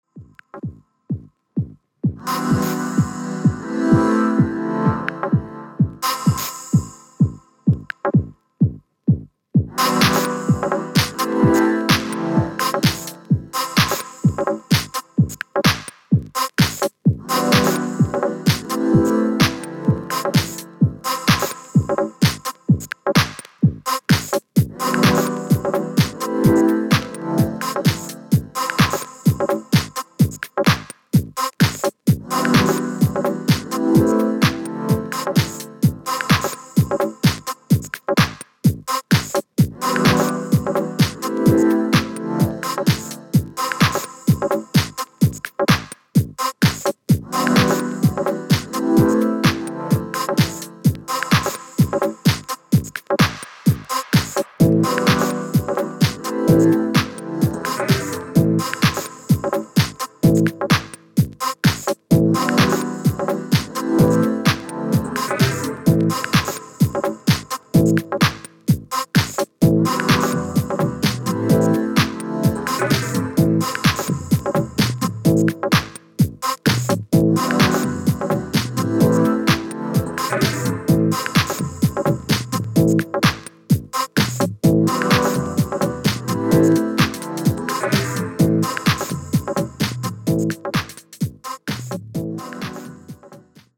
ダビーなサウンドがハマります（笑）